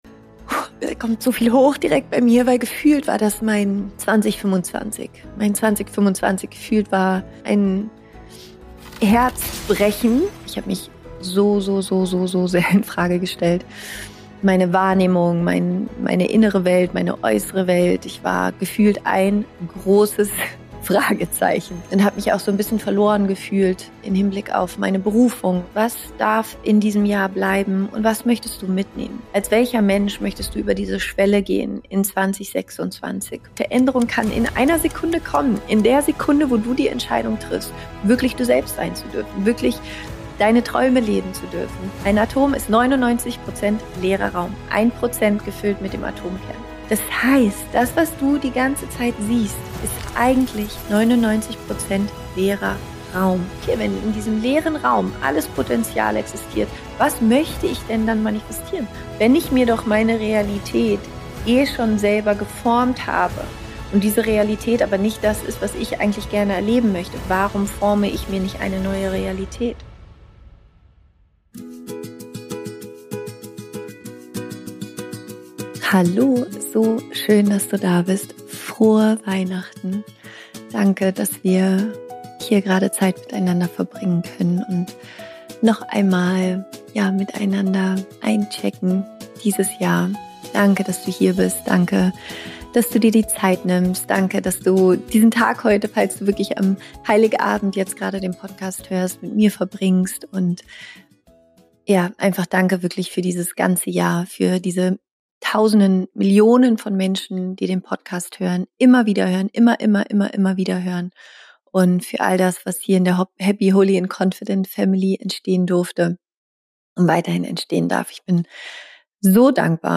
eine geführte Meditation für die Verbindung mit deiner Seele